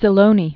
(sĭ-lōnē, sē-lōnĕ), Ignazio Pen name of Secondo Tranquillo. 1900-1978.